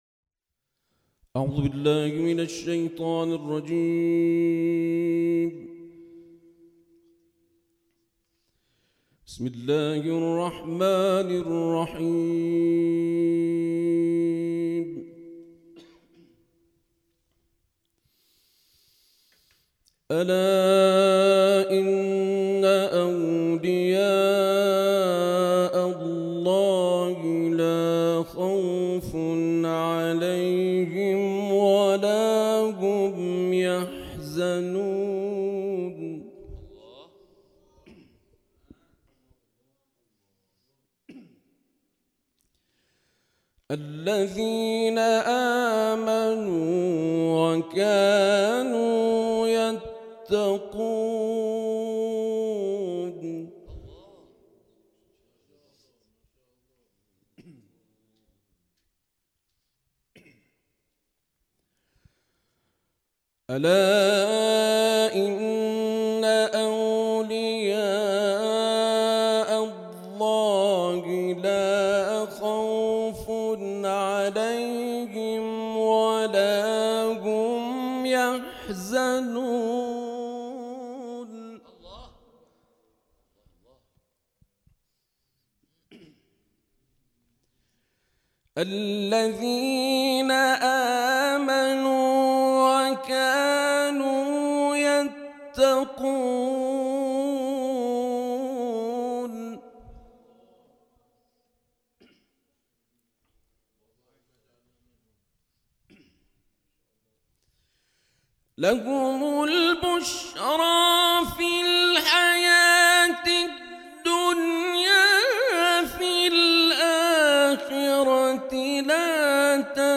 جلسه هفتگی آموزش ترتیل مؤسسه کریمه برگزار شد + صوت
مؤسسه کریمهبه گزارش ایکنا، صد و چهل و نهمین جلسه هفتگی آموزش ترتیل و فنون قرائت قرآن مؤسسه کریمه آل‌رسول(س) قم با حضور قاریان و حافظان جوان و نوجوان در دفتر مرکزی این مؤسسه برگزار شد.
برچسب ها: مؤسسه کریمه آل‌رسول ، جلسه هفتگی قرائت ، آموزش ترتیل